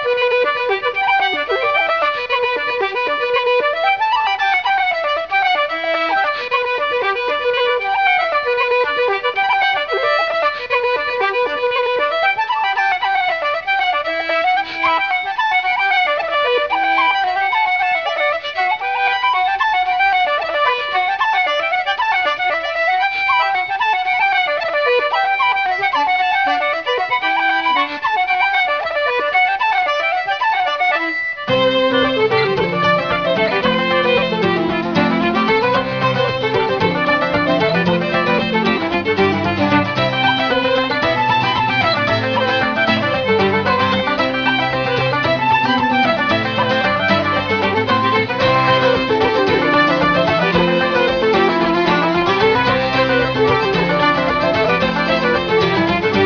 Contemporary/Traditional